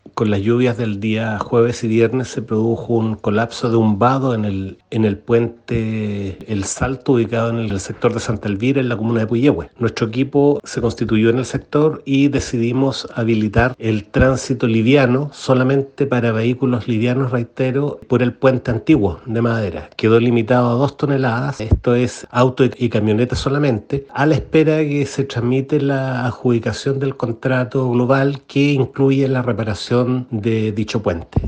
El director regional de Vialidad, Enzo Dellarossa, indicó que se dispuso de un puente de madera paralelo que permite la circulación sólo de vehículos menores a dos toneladas.